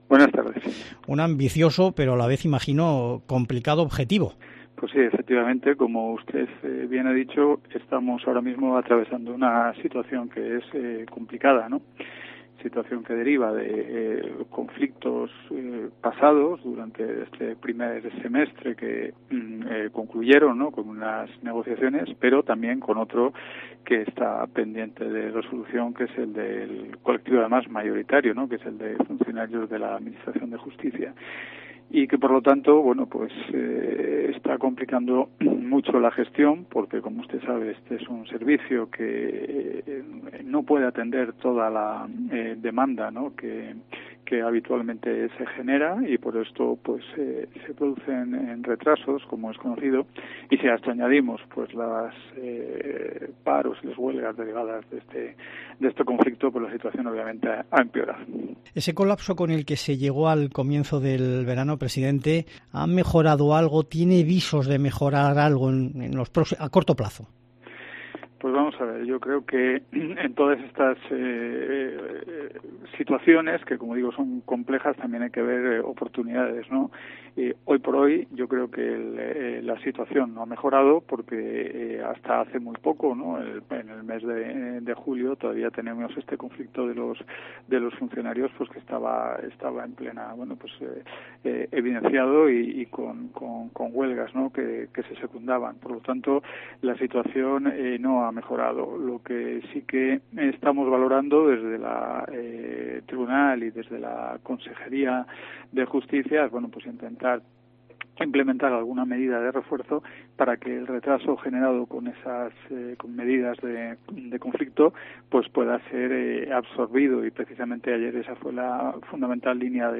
José Luis López del Moral, presidente del TSJC
“No hay que olvidar que los funcionarios de la administración de justicia depusieron sus movilizaciones y volvieron al trabajo porque en periodo electoral no tenían un interlocutor válido en el Ministerio. Una vez haya nuevo Gobierno, retomaron sus legítimas reivindicaciones” asegura López del Moral durante la entrevista.